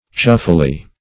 chuffily - definition of chuffily - synonyms, pronunciation, spelling from Free Dictionary Search Result for " chuffily" : The Collaborative International Dictionary of English v.0.48: Chuffily \Chuff"i*ly\, adv.
chuffily.mp3